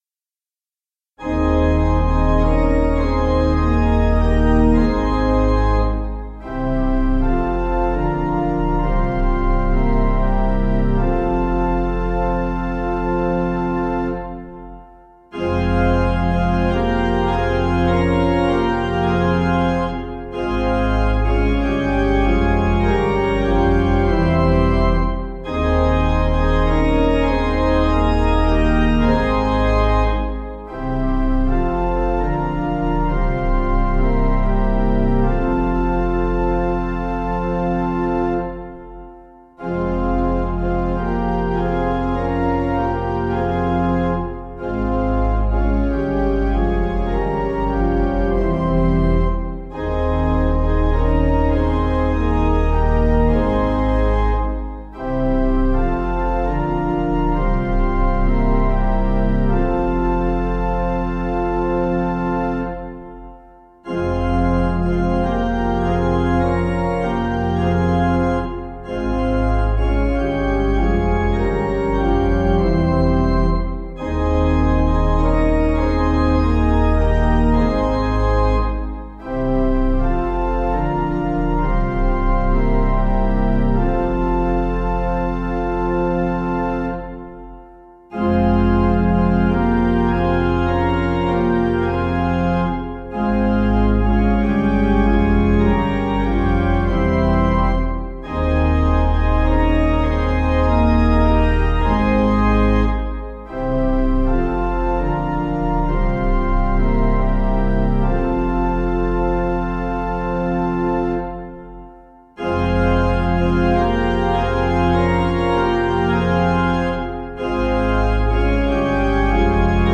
Key: A♭ Major